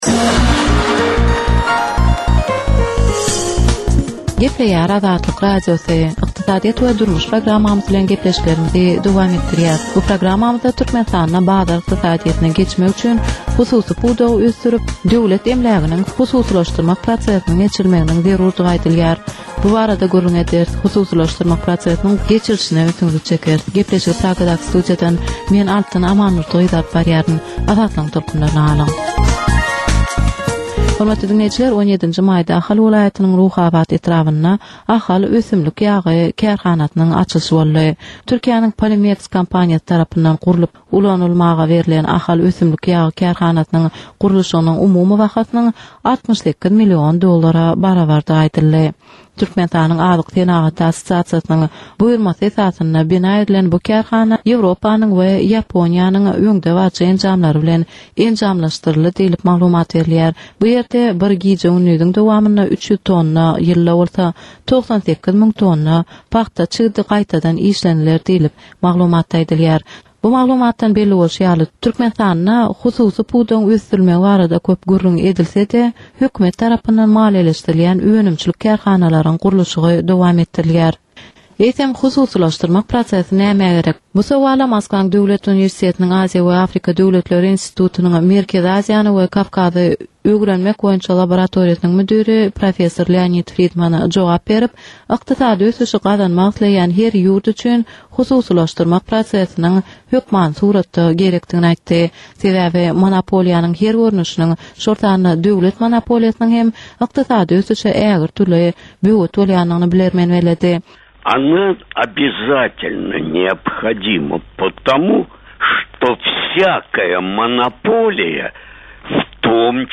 Türkmenistanyň ykdysadyýeti bilen baglanyşykly möhüm meselelere bagyşlanylyp taýýarlanylýan ýörite gepleşik. Bu gepleşikde Türkmenistanyň ykdysadyýeti bilen baglanyşykly, şeýle hem daşary ýurtlaryň tejribeleri bilen baglanyşykly derwaýys meseleler boýnça dürli maglumatlar, synlar, adaty dinleýjileriň, synçylaryň we bilermenleriň pikirleri, teklipleri berilýär.